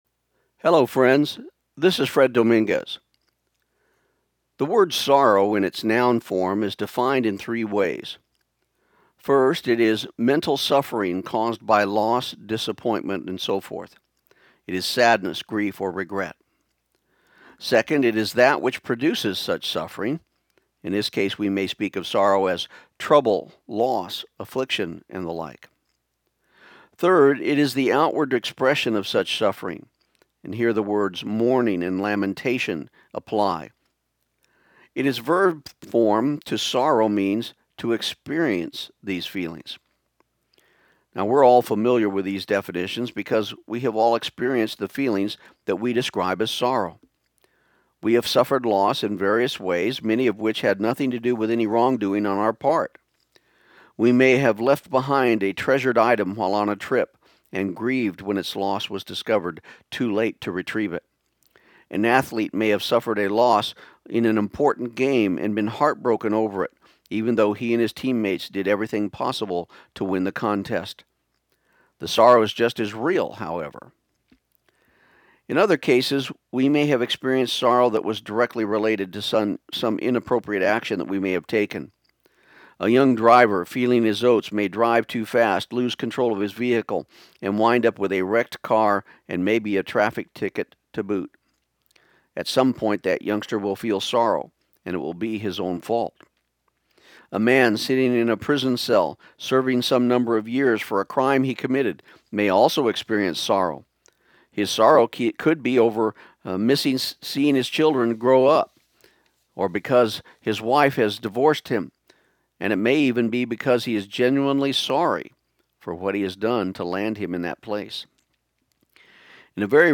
This program aired on KIUN 1400 AM in Pecos, TX on May 31, 2013.